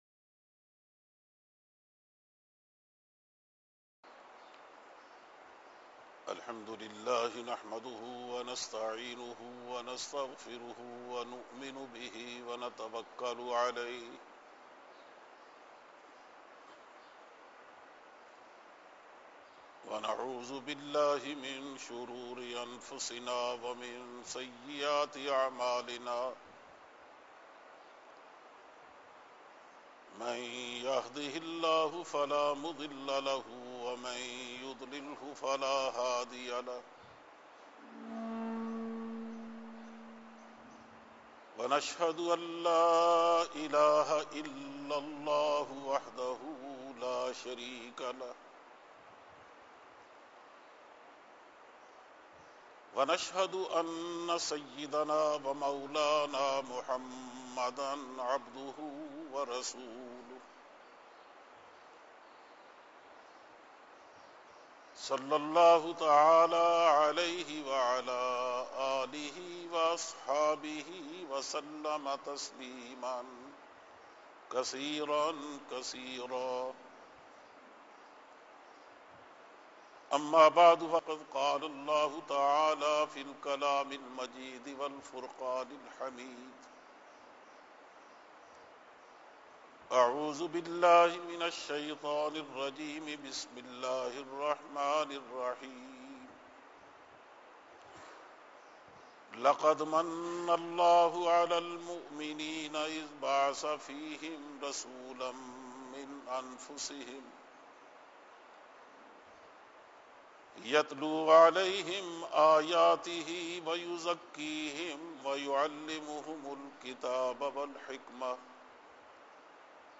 Khutba-e-Juma